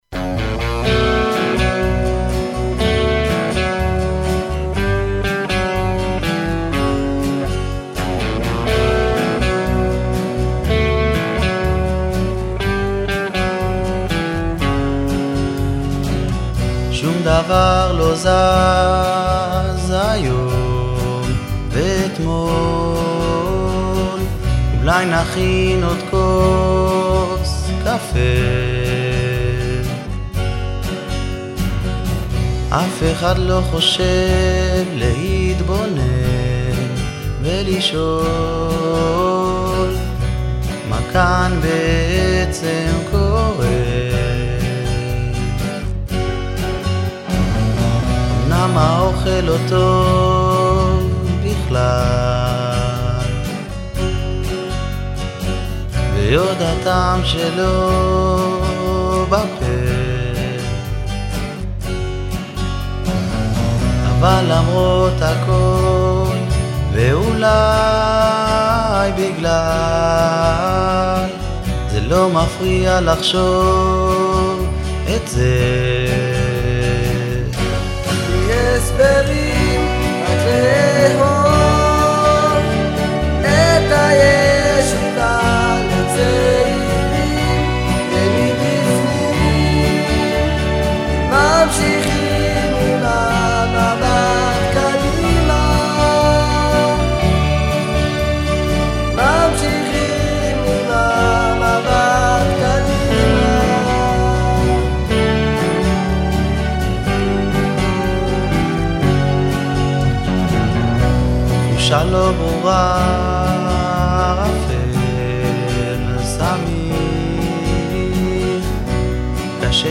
סולו חשמלית
גיטרות ליווי, תופים, midi) strings)